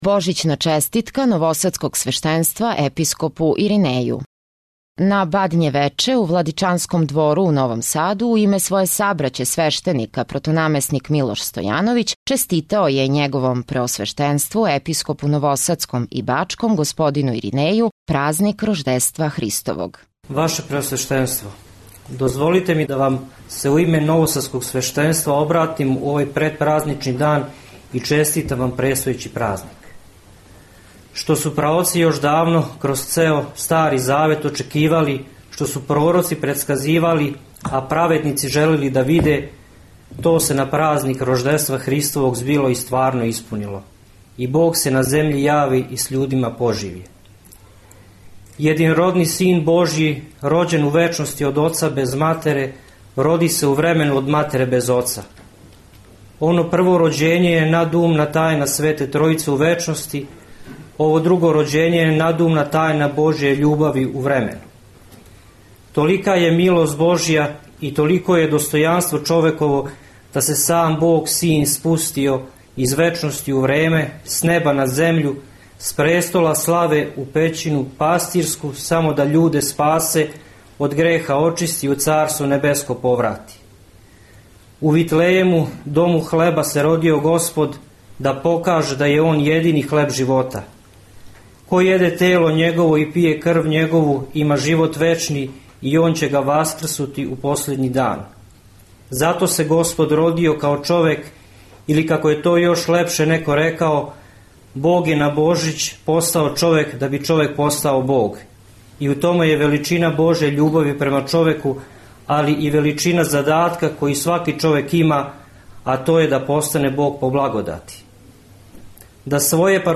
Божићна честитка новосадског свештенства Епископу Иринеју